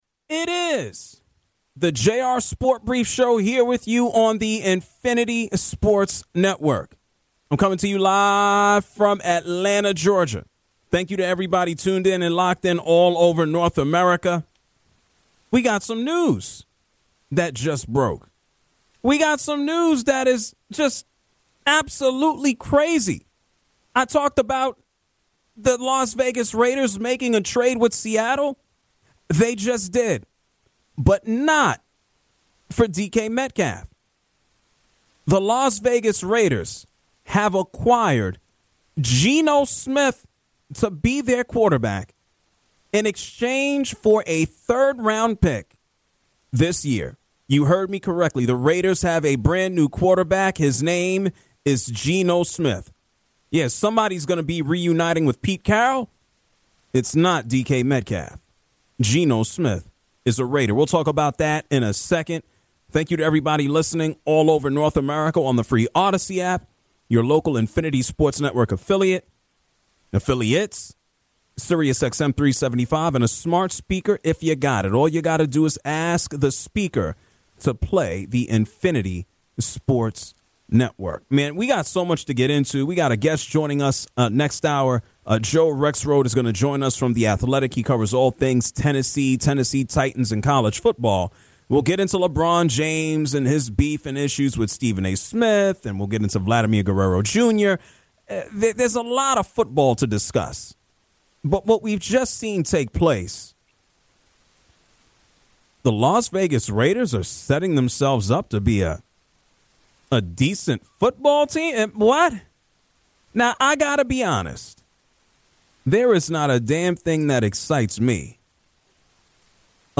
LIVE REACTION: Geno Smith to Raiders! (Hour Two)